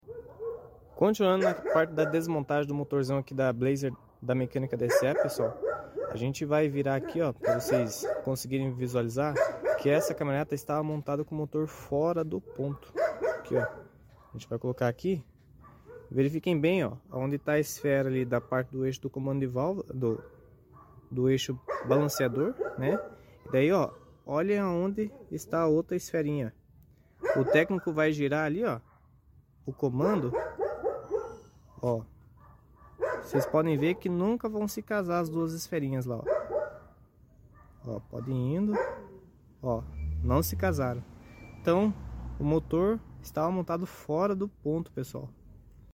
Blazer 4.3 V6 Vortec 1998/1999 sound effects free download
Blazer 4.3 V6 Vortec 1998/1999 Fora do Ponto